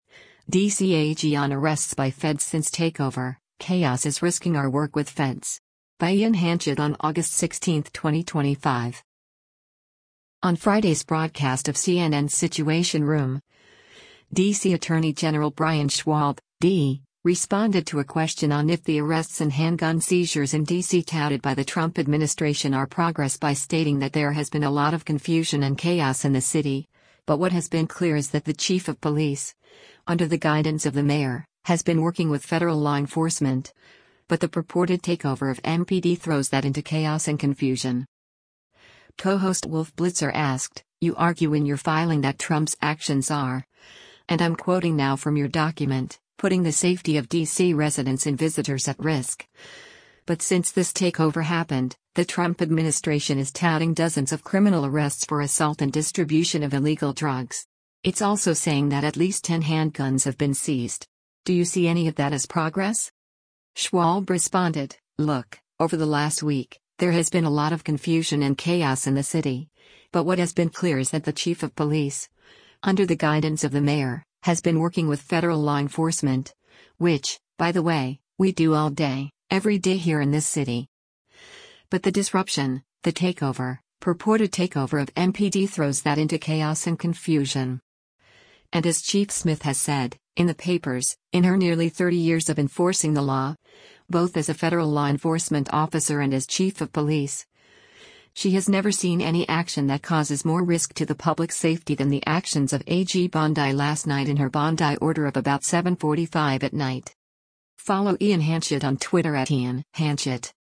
Co-host Wolf Blitzer asked, “You argue in your filing that Trump’s actions are, and I’m quoting now from your document, ‘putting the safety of D.C. residents and visitors at risk,’ but since this takeover happened, the Trump administration is touting dozens of criminal arrests for assault and distribution of illegal drugs.